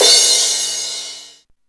CRASH09   -L.wav